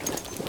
Gear Rustle Redone
tac_gear_13.ogg